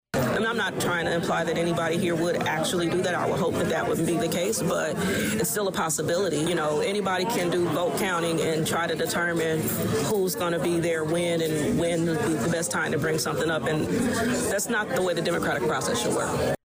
One of the points brought up during discussion came from Vice-Mayor Tricia Teague.  She stated that if you allowed passage by a number smaller than eight, it could open the door to chaos and suspicious maneuvering; as far as pushing for something to be on the agenda when it is known that there will be absences.